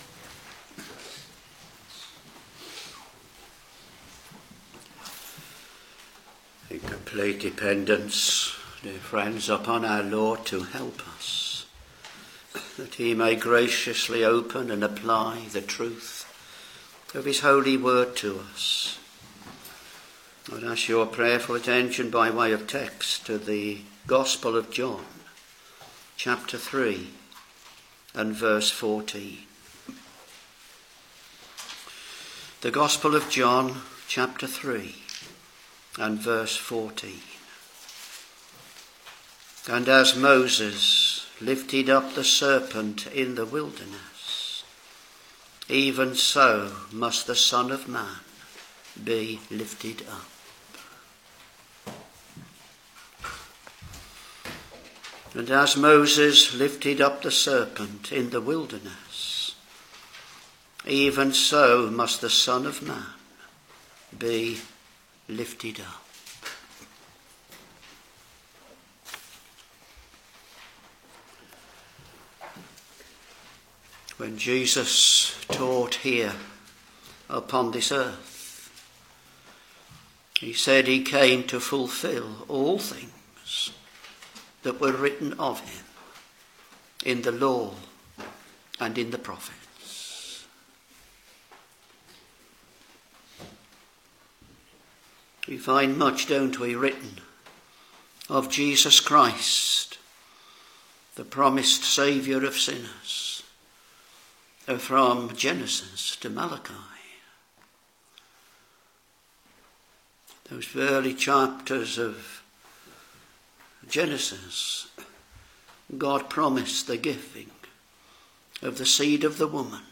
Sermons John Ch.3 v.14 And as Moses lifted up the serpent in the wilderness, even so must the Son of man be lifted up